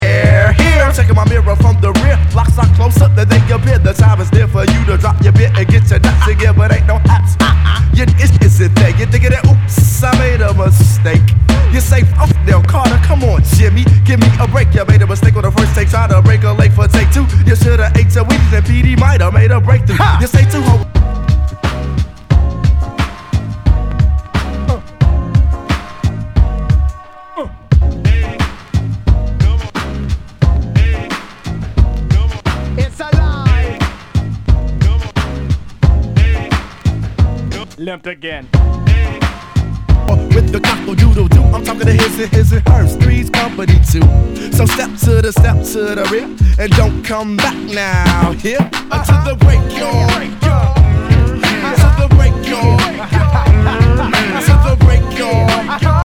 HIPHOP/R&B
盤に傷あり全体にチリノイズが入ります